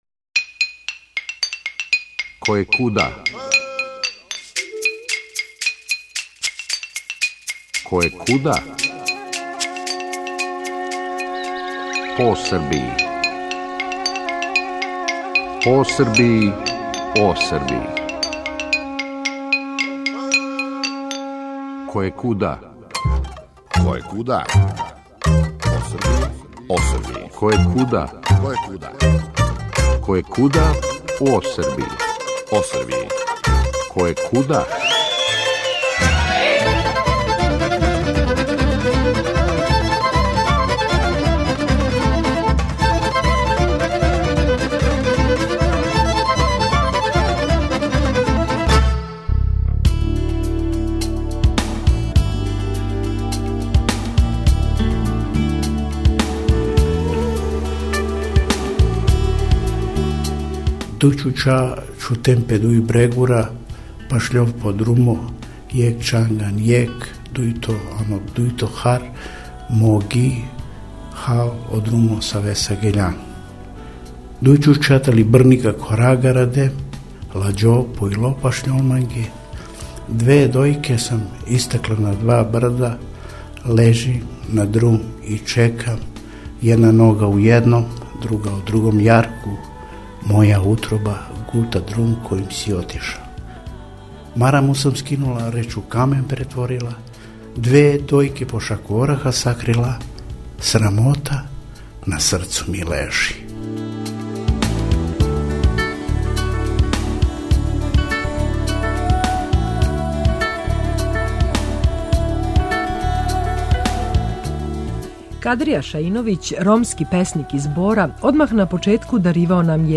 Zabeležili smo kako tamošnji Romi žive, a posebno smo razgovarali o običajima kojih se pridržavaju i koji su važni za njihovu tradiciju. Najviše smo saznali o proslavljanju Tetkice Bibije, koja važi za čudotvorno biće koje spasava Rome od teških bolesti, pre svega kuge, a posebno se veruje da je naklonjena deci.